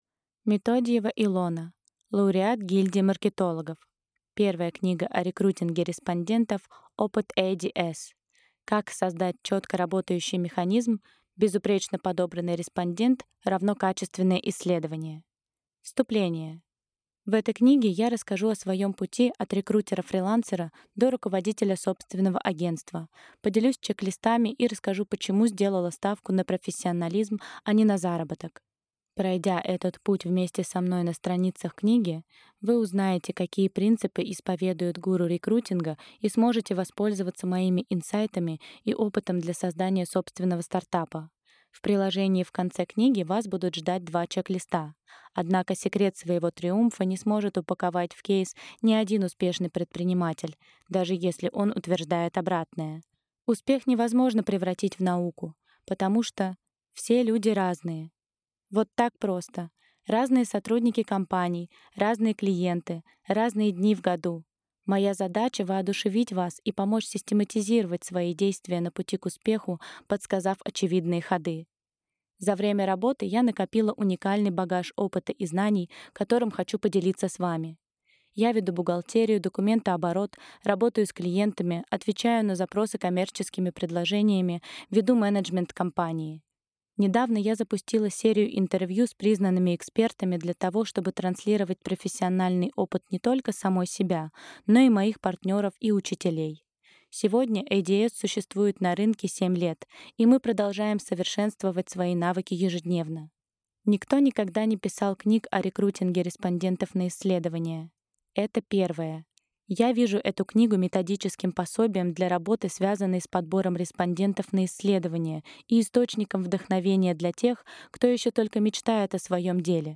Аудиокнига Первая книга о рекрутинге респондентов. Опыт ADS. История создания ниши | Библиотека аудиокниг